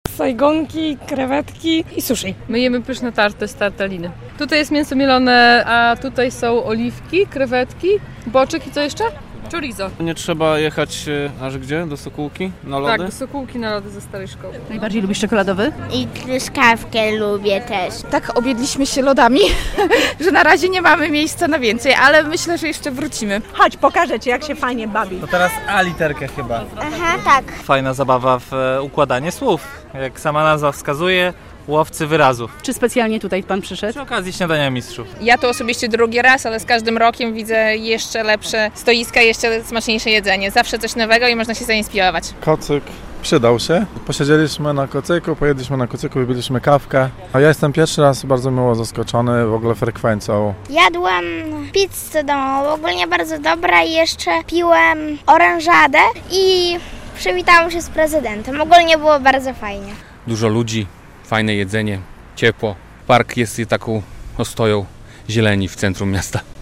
"Podlaskie Śniadanie Mistrzów" w ogrodach Pałacu Branickich w Białymstoku - relacja